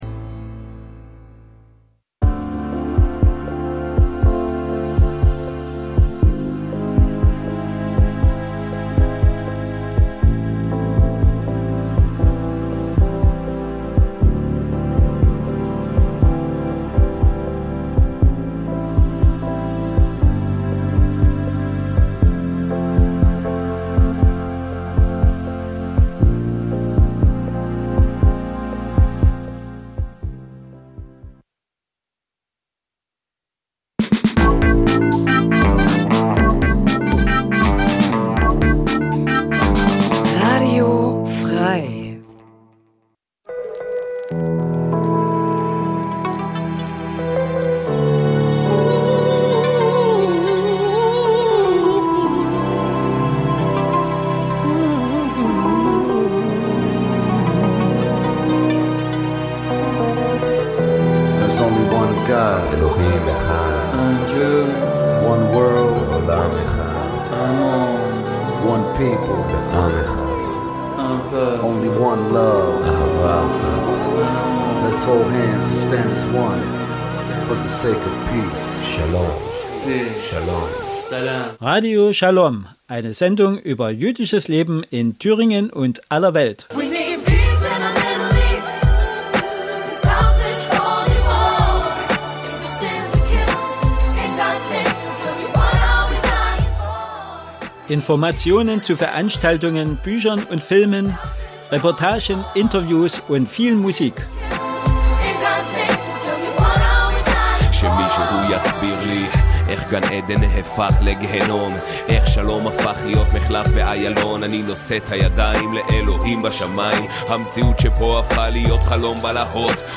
Radio Schalom berichtet �ber j�disches Leben in Deutschland, Diskussionen �ber Tradition und Religion, Juden in Israel und in der Diaspora sowie Musik aus Israel und anderen Teilen der Welt.